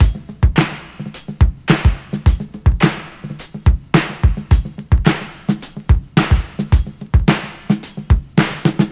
• 108 Bpm 80's Drum Loop G# Key.wav
Free breakbeat sample - kick tuned to the G# note. Loudest frequency: 986Hz